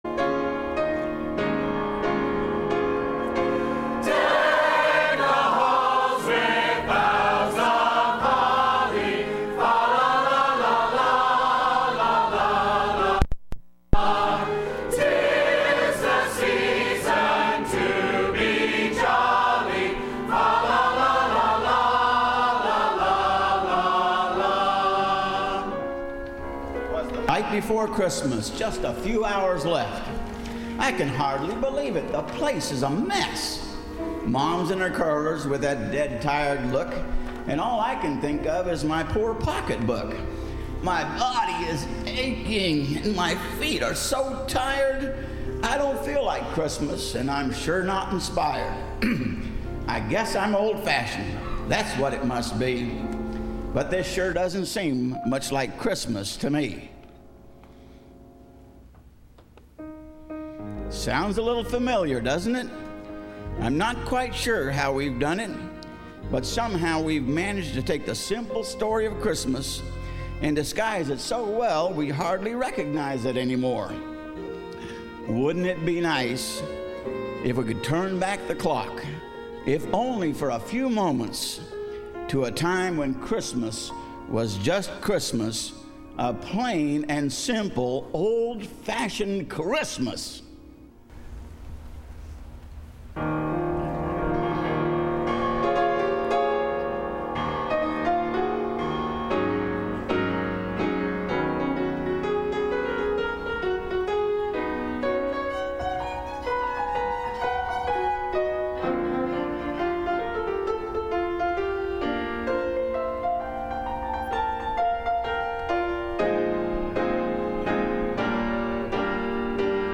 Cantata – Landmark Baptist Church
Service Type: Sunday Evening